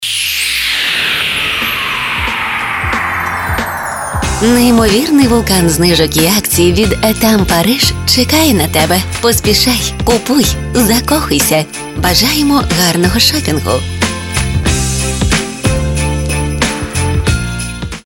FireBrands – експерти зі звукового дизайну для радіо- і TV-реклами.